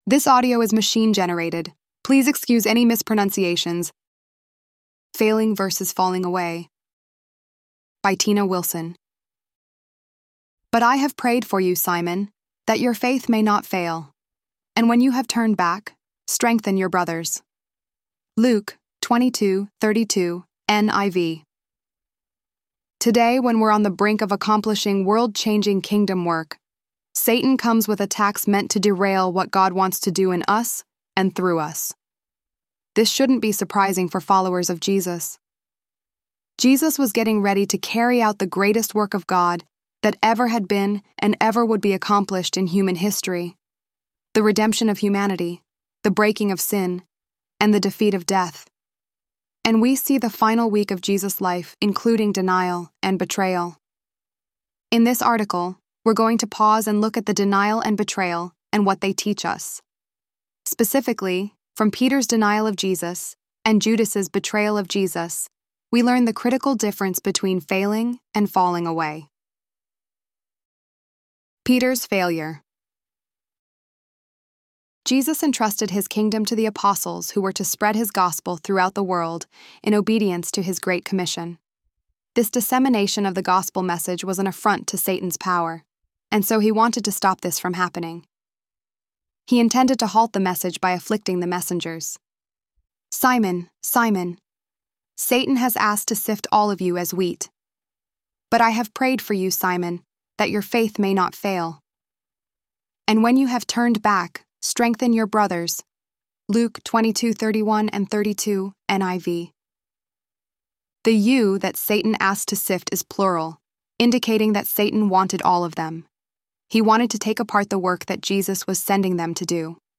ElevenLabs_10.1.mp3